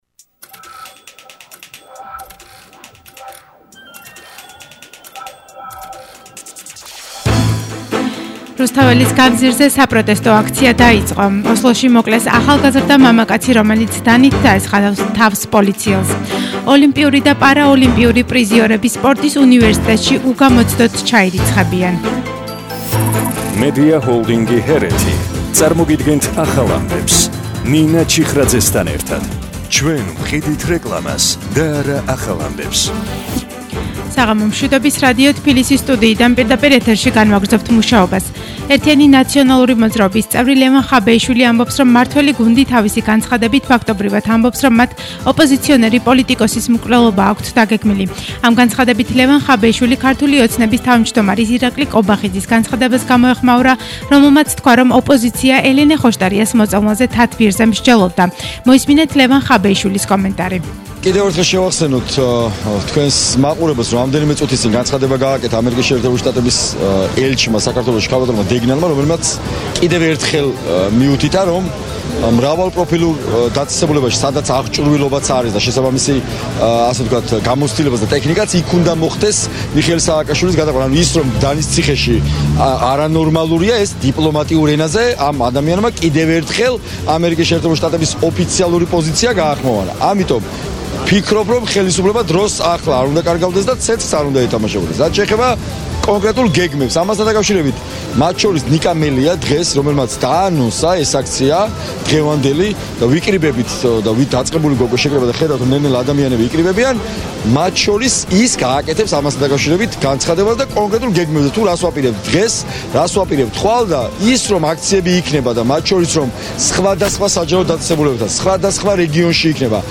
ახალი ამბები 20:00 საათზე –9/11/21